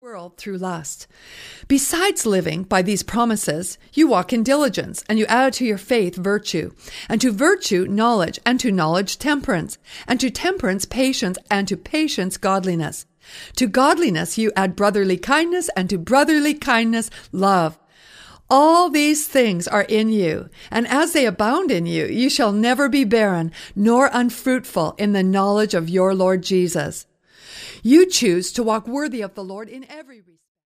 Music CD